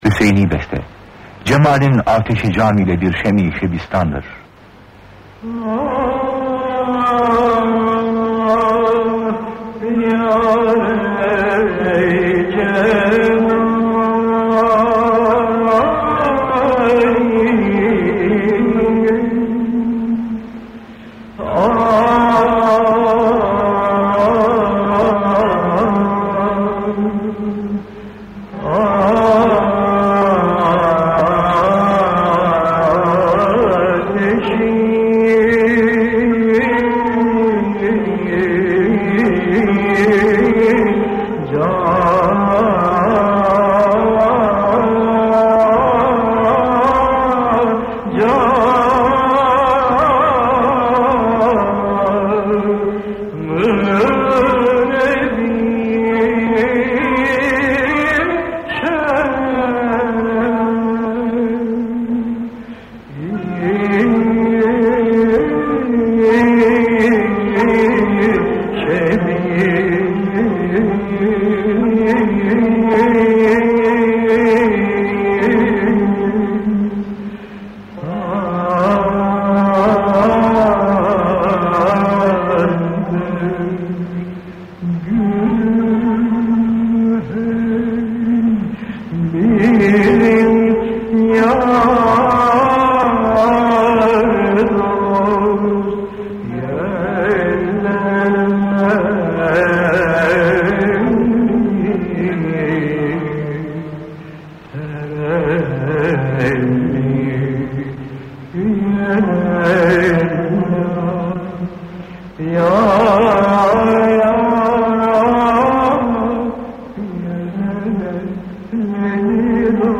Makam: Hüseyni Form: Beste Usûl: Çenber (Ağır)